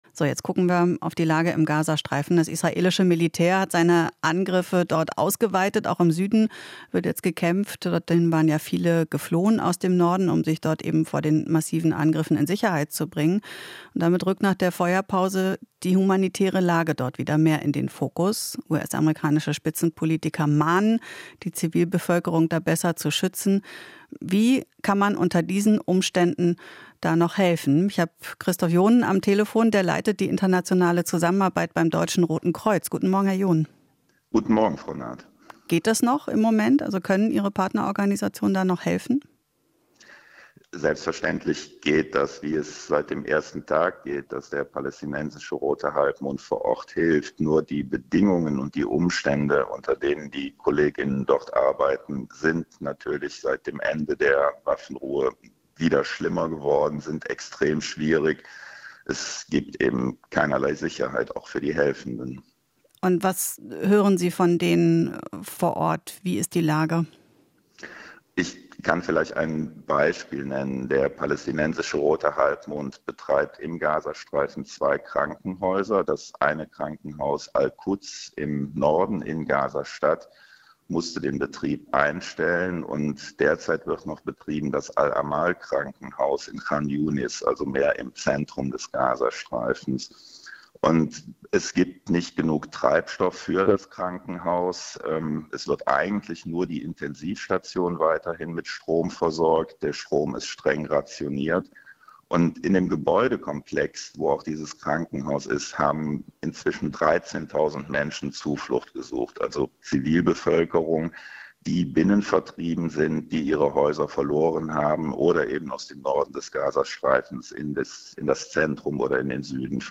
Interview - Rotes Kreuz: Feuerpause hat keine nachhaltige Verbesserung gebracht